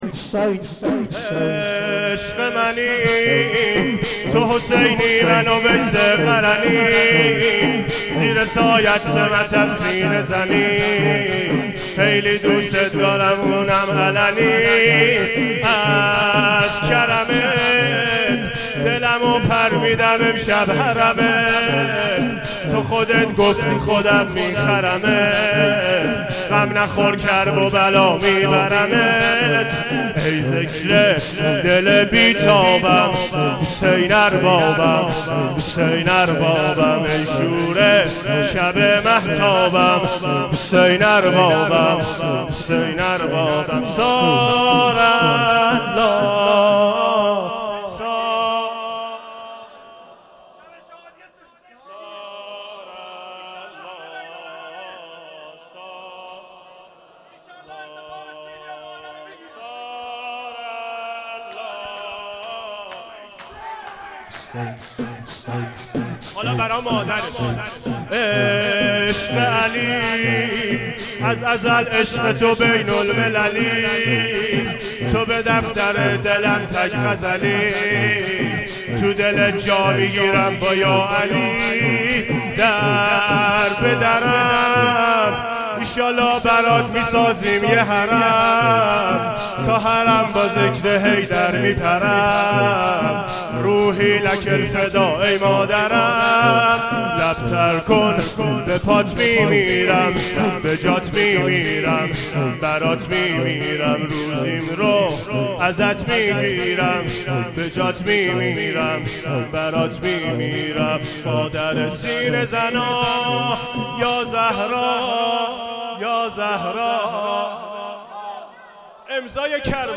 شور2شب چهارم فاطمیه
مداحی